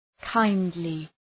Προφορά
{‘kaındlı}